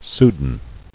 [sou·dan]